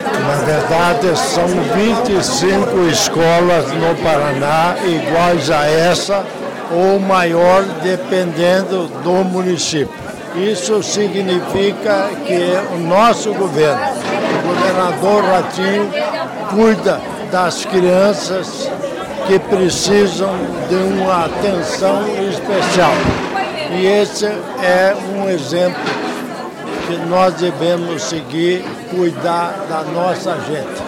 Sonora do governador em exercício Darci Piana sobre a inauguração da Apae em Altamira do Paraná | Governo do Estado do Paraná